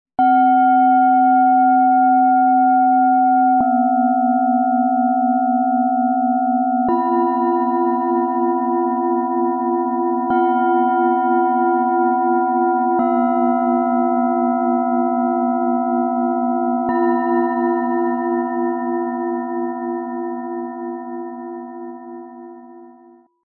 Verbindung von Körper & Raum - 3 handgefertigte Klangschalen für Gruppenrituale & Meditation Ø 13,7 - 18,2 cm, 2,19 kg
Dieses Set schenkt Dir einen durchgängigen Klangbogen vom ruhigen Bauchraum bis zum lichtvollen Kopfklang.
Im Sound-Player - Jetzt reinhören kannst Du den Original-Klang dieser Schalen hören - vom tiefen Bauchklang bis zur klaren oberen Frequenz. Ein harmonischer Dreiklang für innere Ausrichtung und Gruppenprozesse.
MaterialBronze